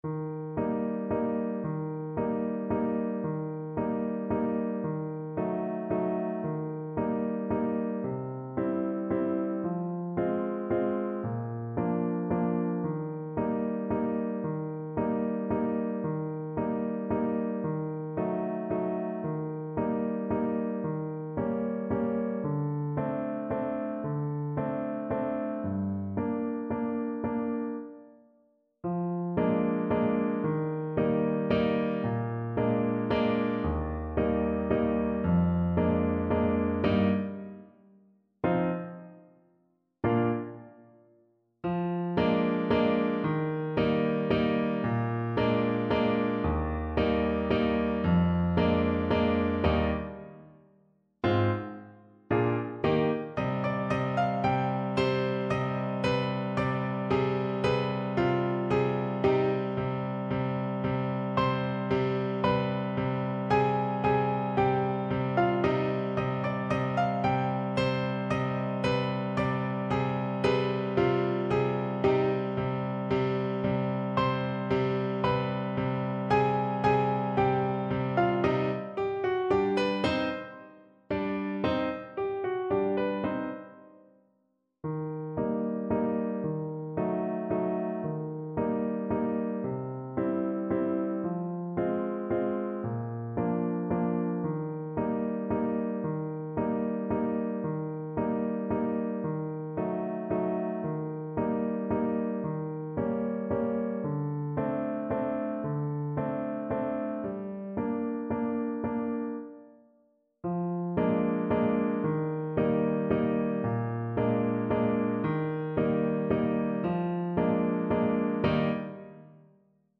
Play (or use space bar on your keyboard) Pause Music Playalong - Piano Accompaniment Playalong Band Accompaniment not yet available transpose reset tempo print settings full screen
French Horn
Eb major (Sounding Pitch) Bb major (French Horn in F) (View more Eb major Music for French Horn )
3/4 (View more 3/4 Music)
=150 Vivace (View more music marked Vivace)
Classical (View more Classical French Horn Music)